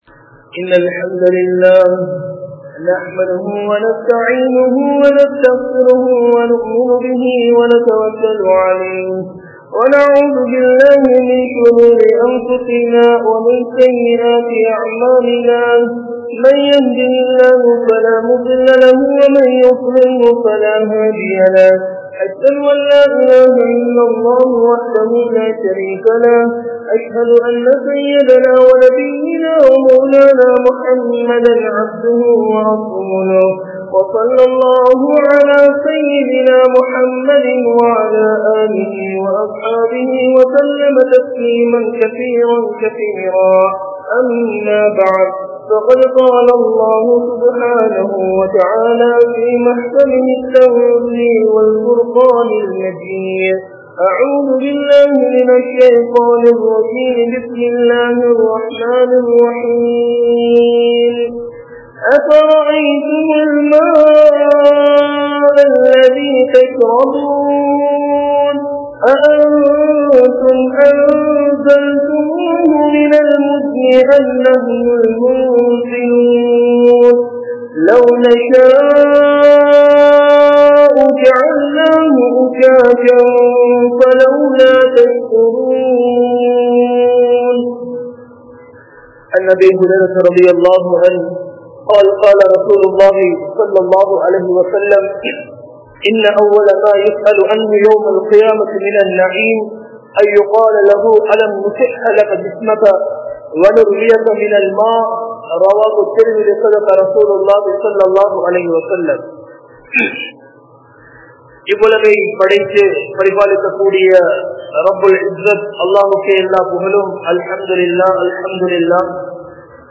Islam Koorum Thanneerin Mukkiyathuvam(இஸ்லாம் கூறும் தண்ணீரின் முக்கியத்துவம்) | Audio Bayans | All Ceylon Muslim Youth Community | Addalaichenai
Colombo 04, Majma Ul Khairah Jumua Masjith (Nimal Road)